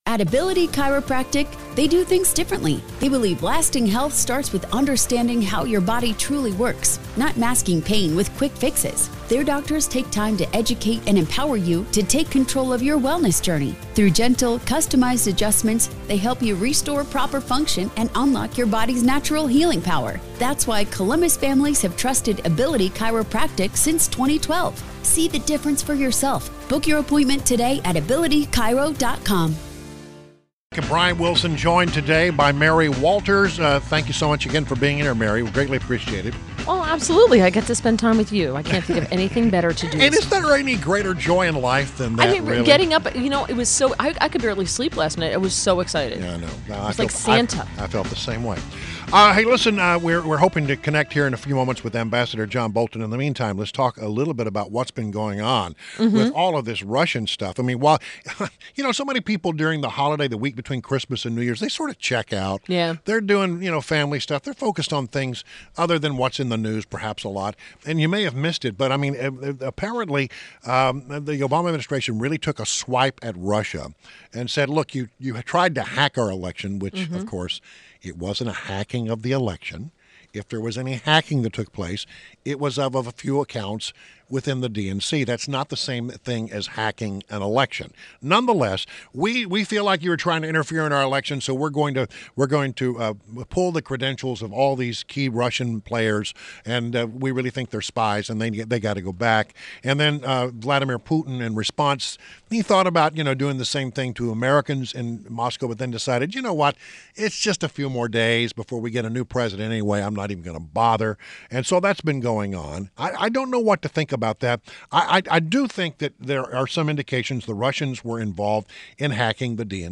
INTERVIEW – AMB. JOHN BOLTON – former UN Ambassador